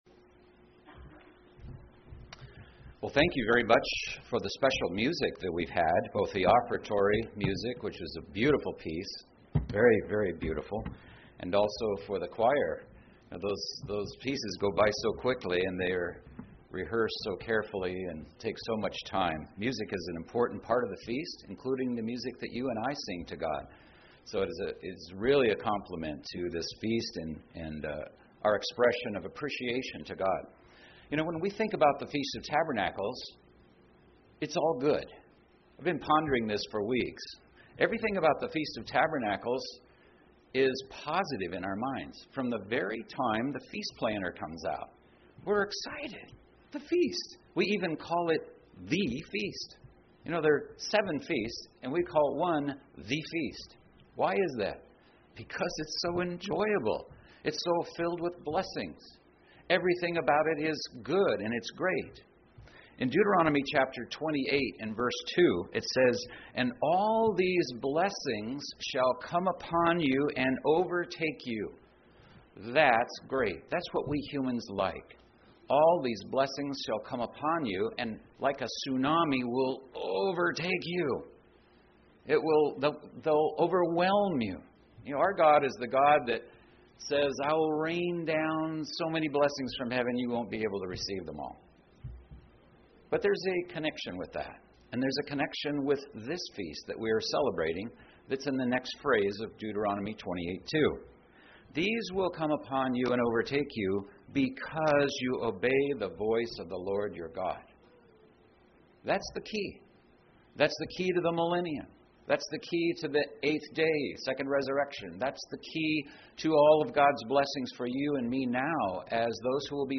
This sermon was given at the Osoyoos Lake, British Columbia 2018 Feast site.